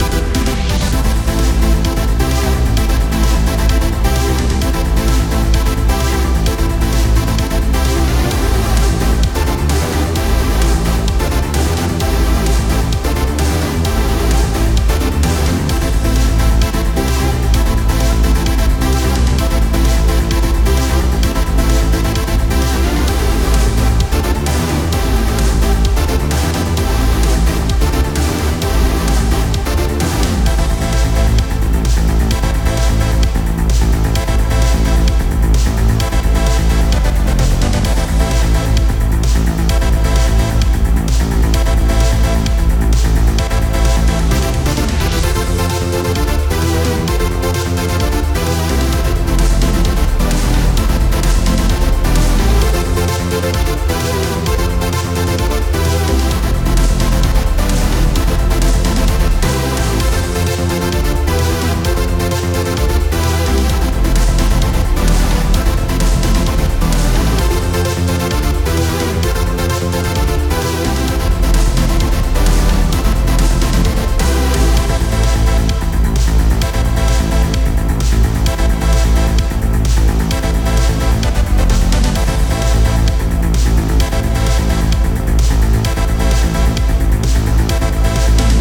Music for Battle theme.